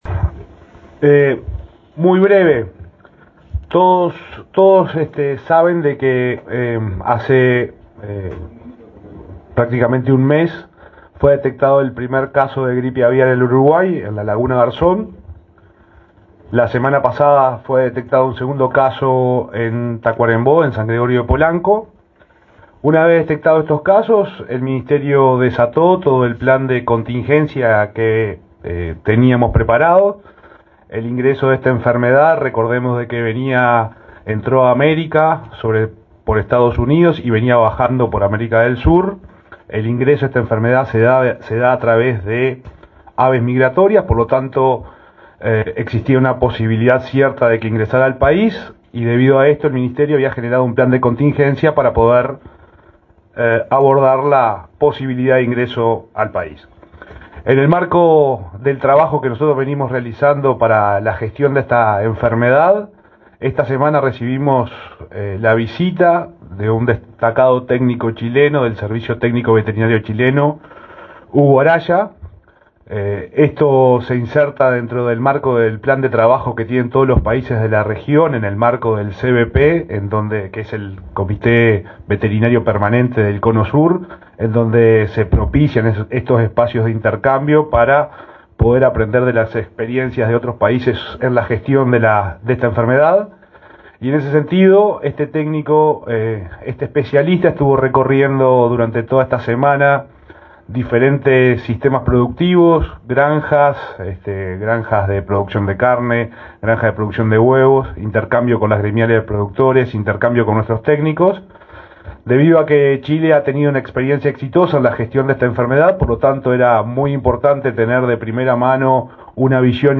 Palabras del subsecretario de Ganadería, Juan Ignacio Buffa
El subsecretario de Ganadería, Juan Ignacio Buffa, informó a la prensa sobre la situación de la gripe aviar en el país.